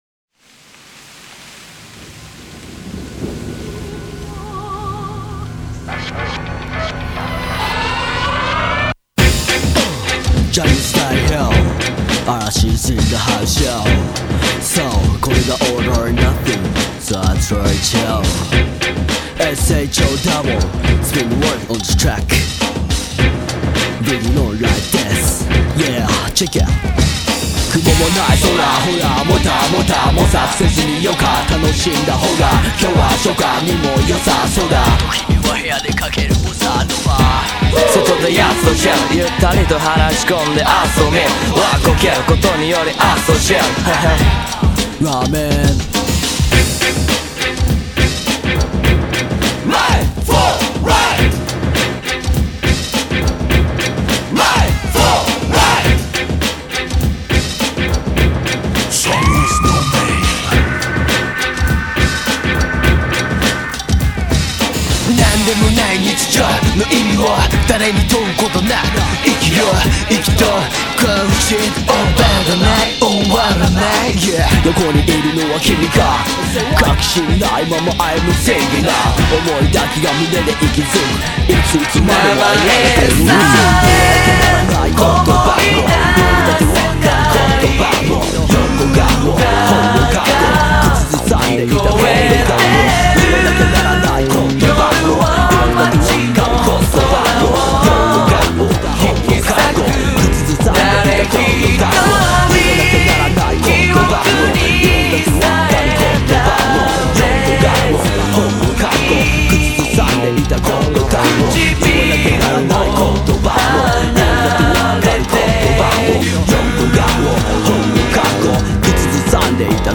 sounds like it could be a cheesy hip-hop track
the group’s main rapper